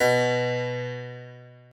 Harpsicord
b2.mp3